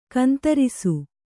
♪ kantarisu